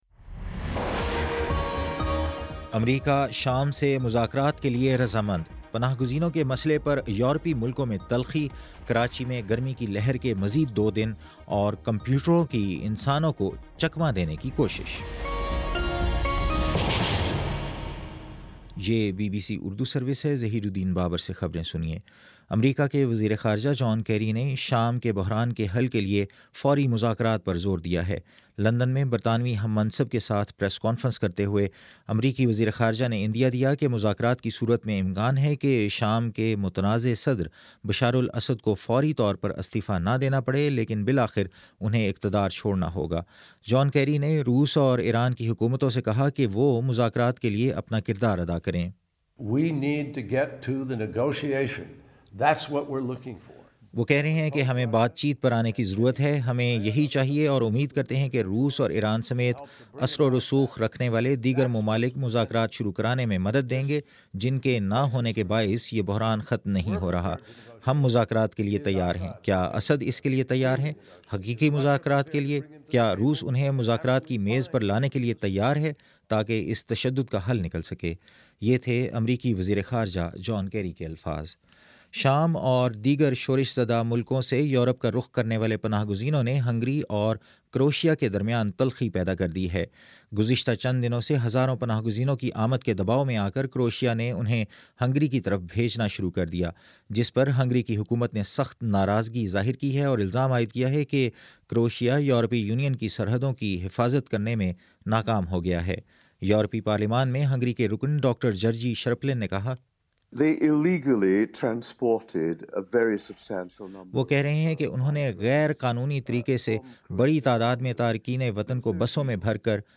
ستمبر19 : شام سات بجے کا نیوز بُلیٹن